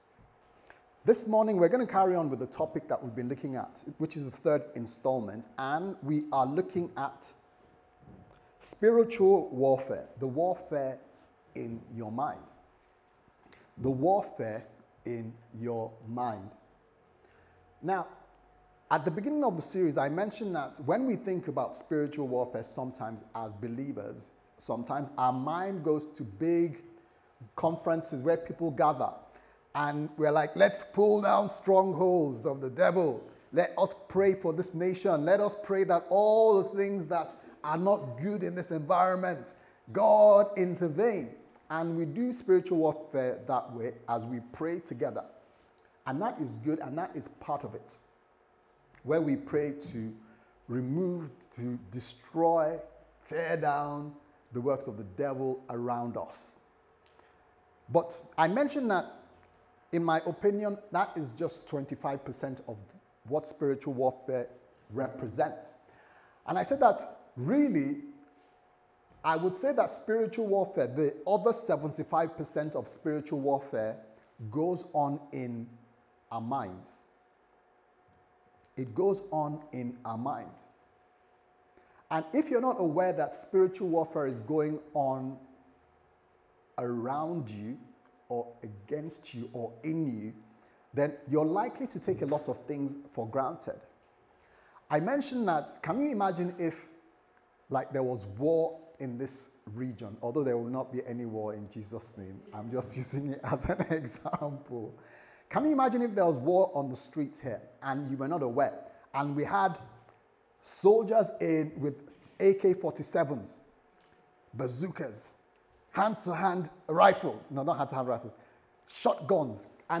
The Warfare In Your Mind Service Type: Sunday Service Sermon « The Warfare In Your Mind